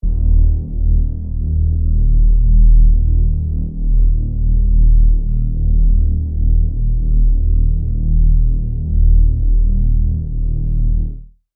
808s
Bass The Interlude That Never Ends.wav